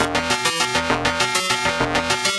Index of /musicradar/8-bit-bonanza-samples/FM Arp Loops
CS_FMArp C_100-E.wav